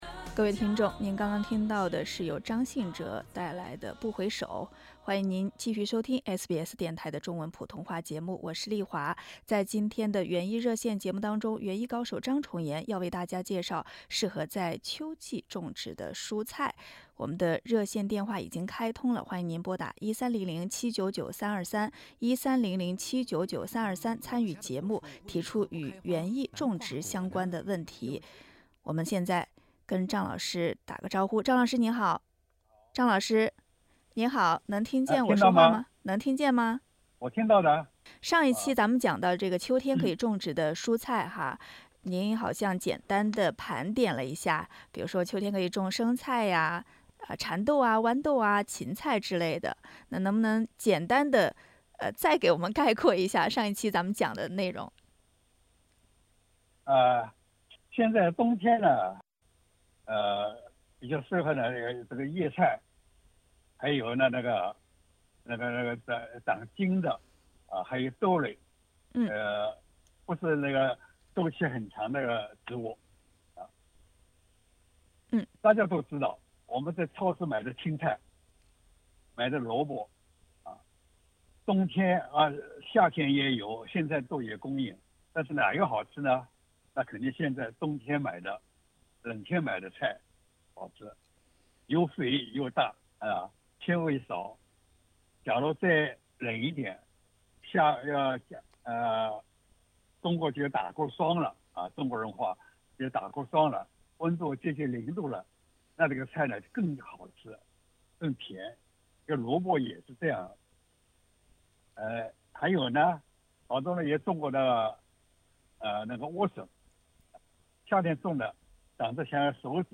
听众也打进了电话咨询与园艺种植相关的问题，比如自家柠檬树为什么长不大以及藤三七的花能否食用等等。